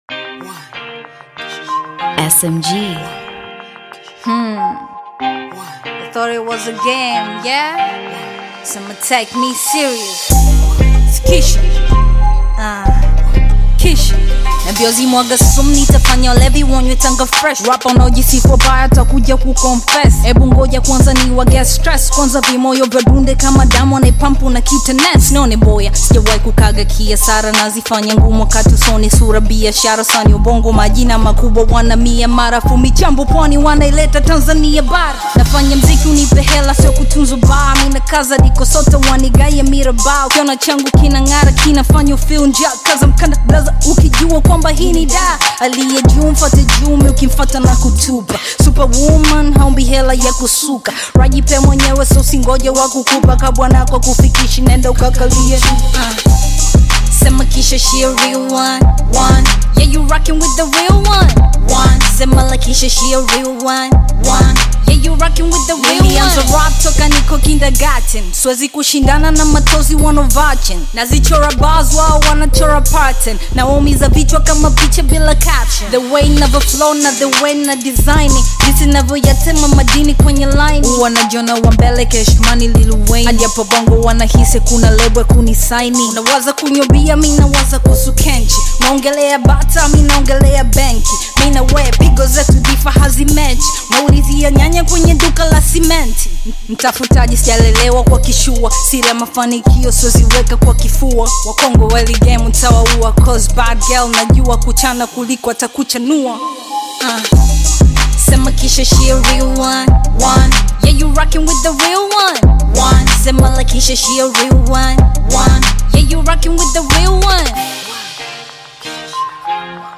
Hip-Hop/Rap single